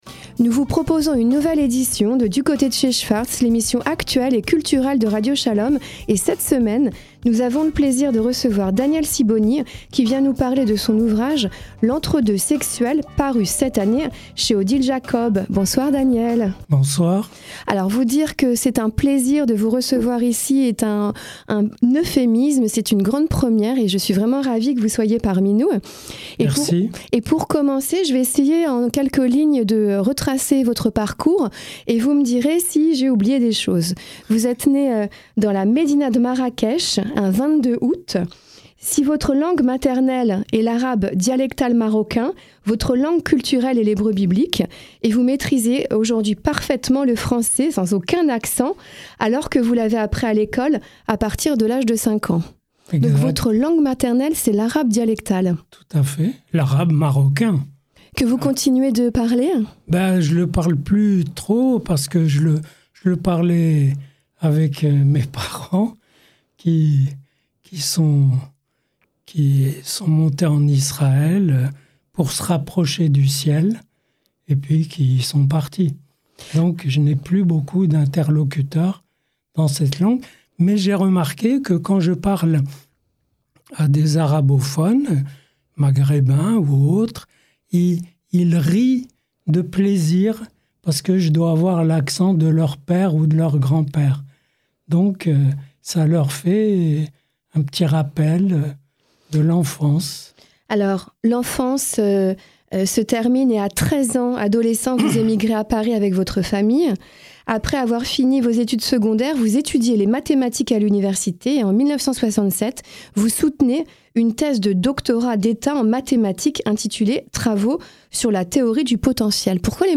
Notre invité est Daniel Sibony qui nous parle de son dernier livre: « L’Entre-deux sexuel » (Odile Jacob).